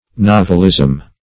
novelism - definition of novelism - synonyms, pronunciation, spelling from Free Dictionary Search Result for " novelism" : The Collaborative International Dictionary of English v.0.48: Novelism \Nov"el*ism\, n. Innovation.